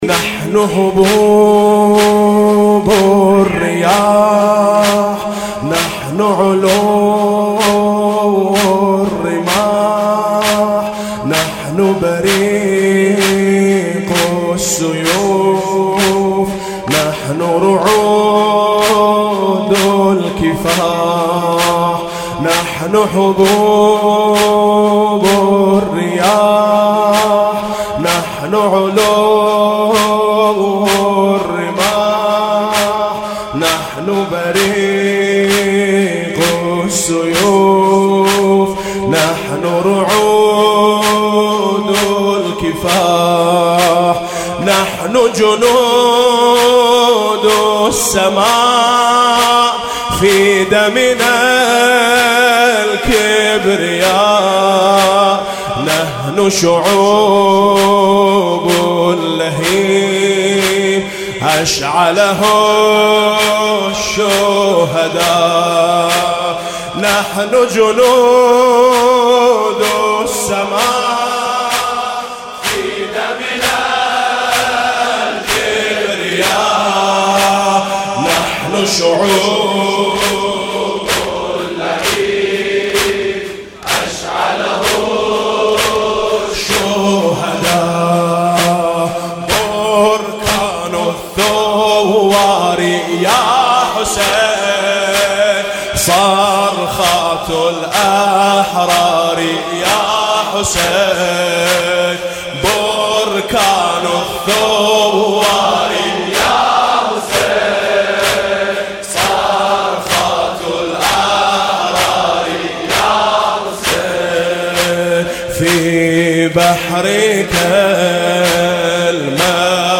لطميات حسينية